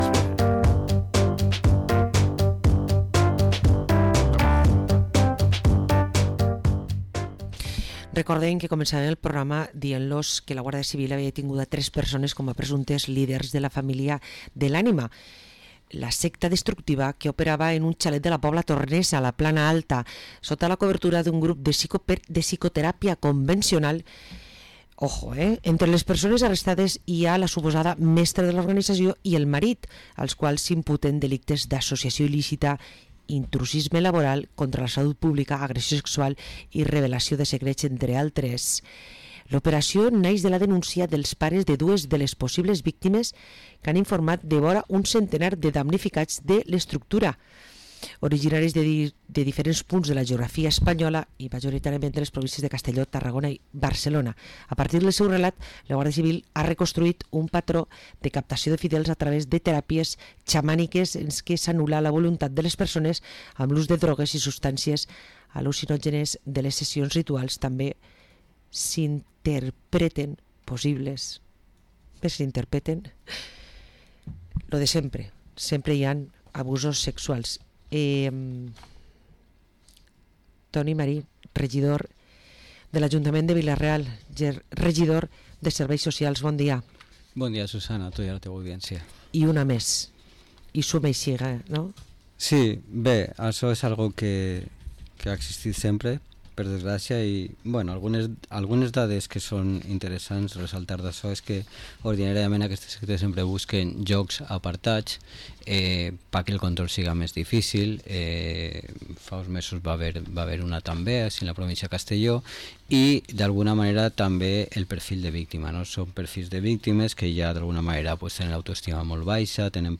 Parlem amb Toni Marín, regidor de Serveis Socials a l´ajuntament de Vila-real